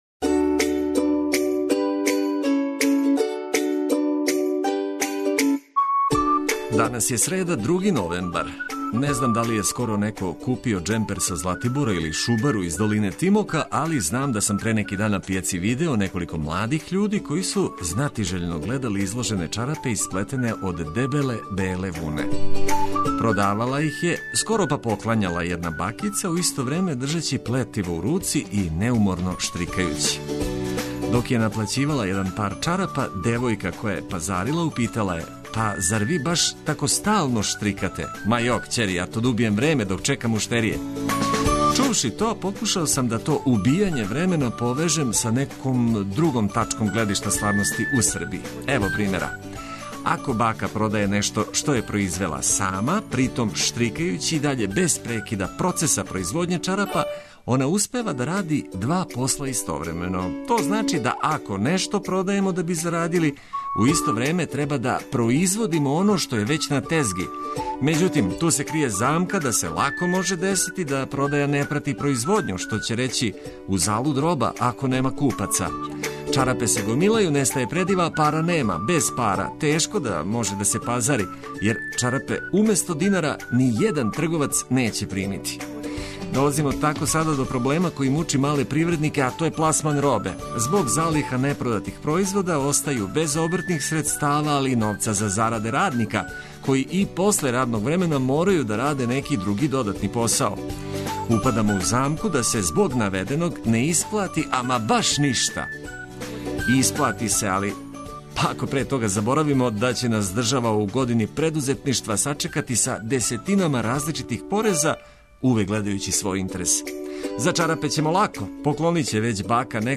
Уз лепе и корисне приче, добро расположење и музику која мотивише дан почиње много лепше.
За све љубитеље спорта и овога јутра емитујемо спортске вести.